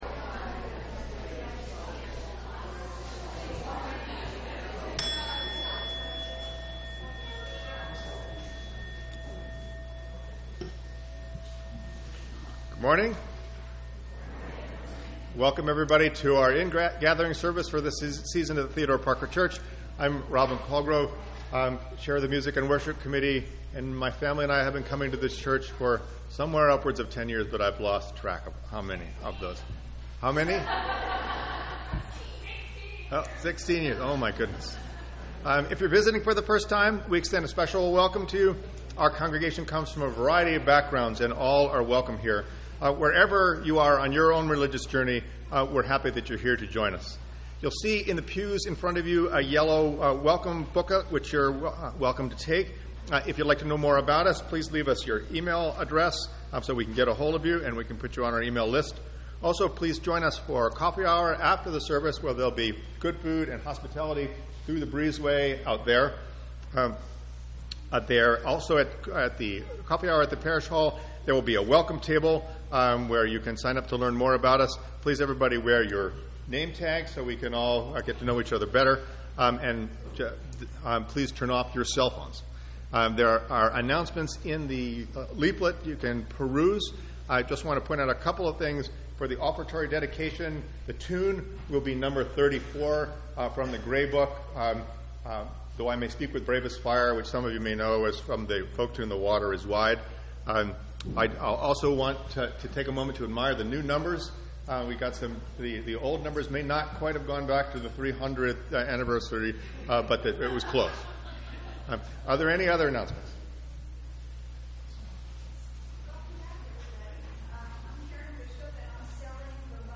Ingathering Service